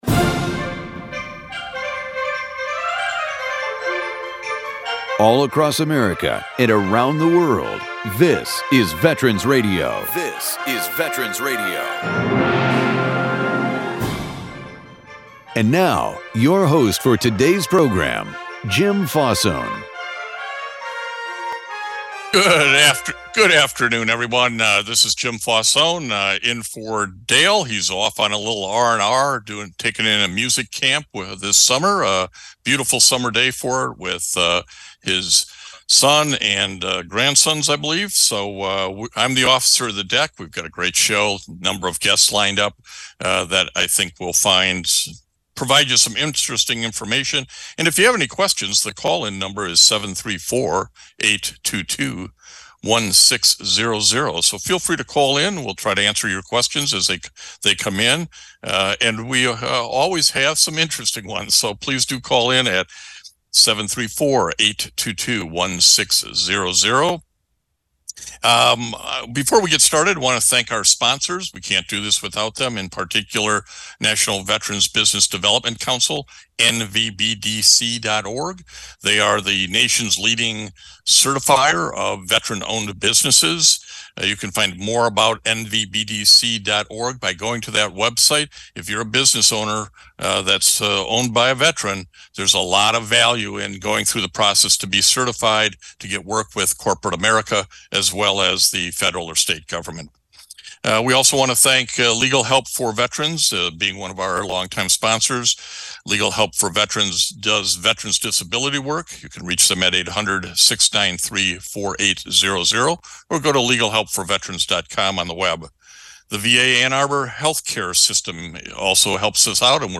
Expert Panel answers questions regarding your military benefits earned by serving in the US Armed Forces.